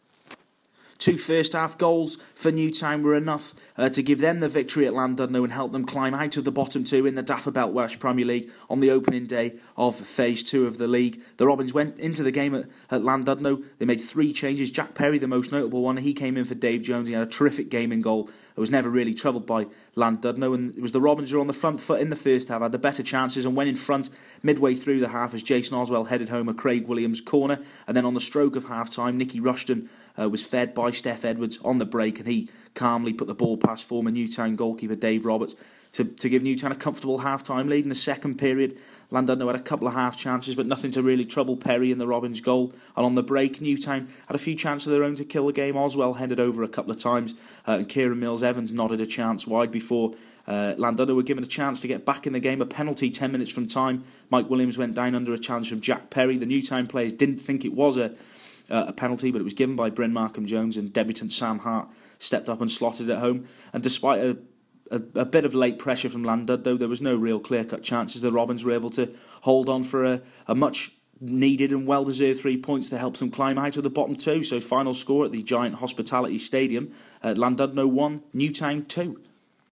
AUDIO REPORT - Llandudno 1-2 Robins